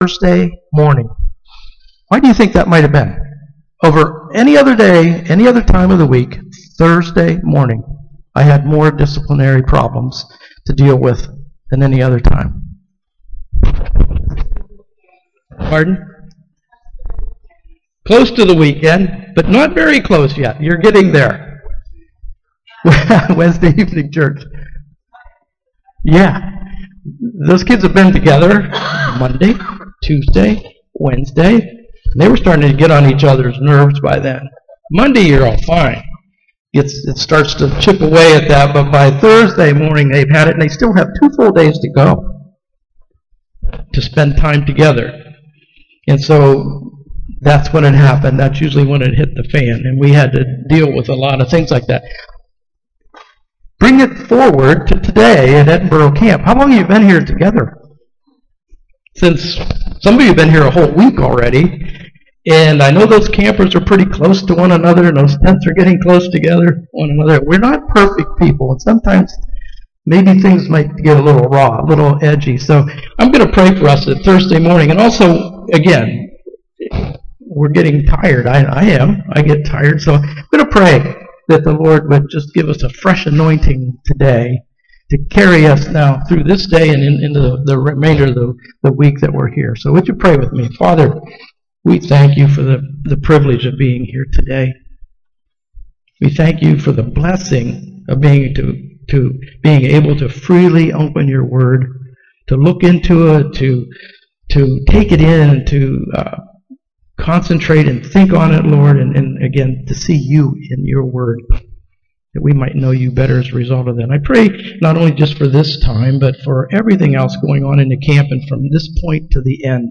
Family Camp 2023